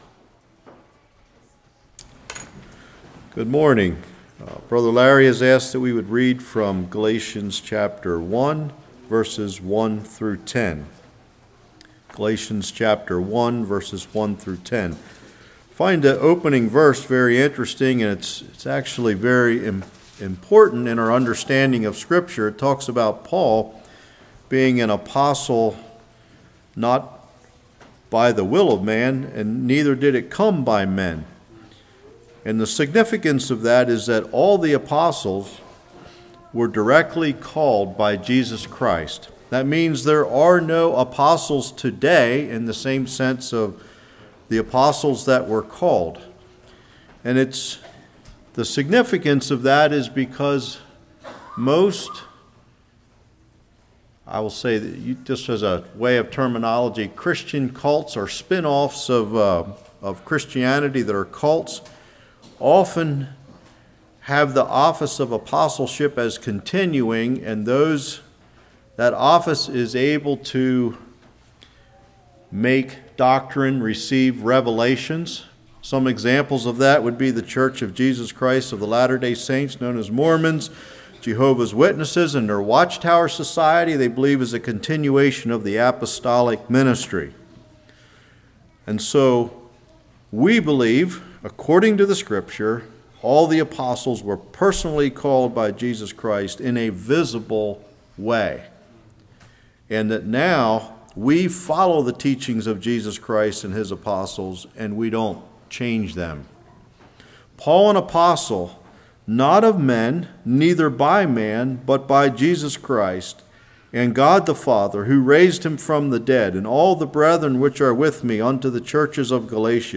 Passage: Galatians 1:1-10 Service Type: Morning